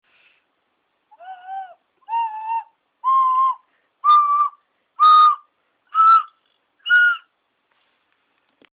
これはオカリナ式です。
一応この笛の音を載せておきますの
音階
kokarina.mp3